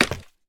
minecraft / sounds / step / scaffold5.ogg
scaffold5.ogg